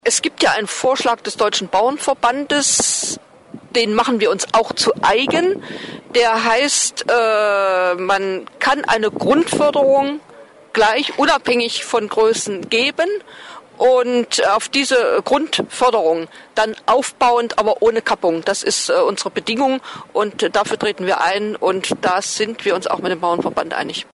Dem pflichtete auch Christine Lieberknecht, die im Gespräch mit der nnz deutlich gegen eine Kappung